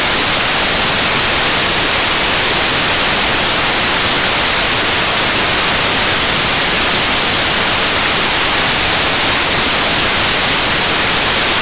raining.mp3